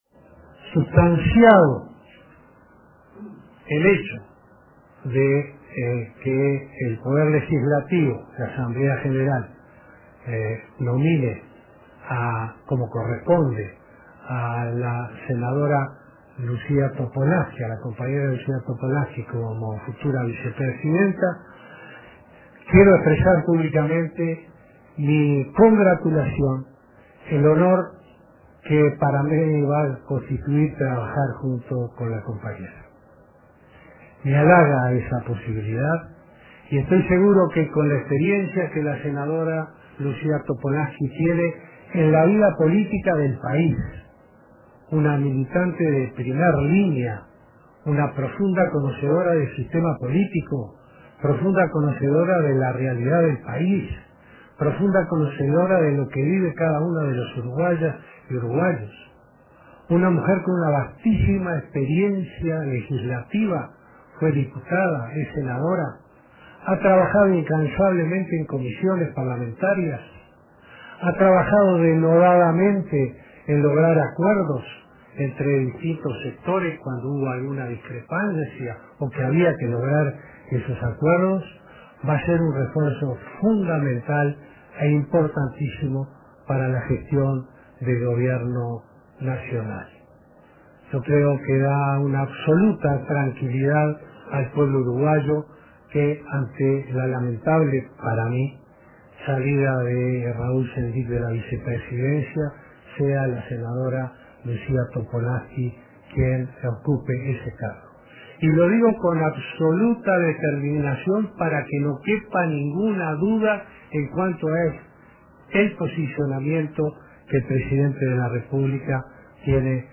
El presidente Vázquez elogió la figura de la senadora Lucía Topolansky y expresó su congratulación y el honor que significará trabajar junto a ella cuando sea designada vicepresidenta de la República. El mandatario dijo que Topolansky es una profunda conocedora del sistema político y de la realidad del país y tiene una vastísima experiencia legislativa.”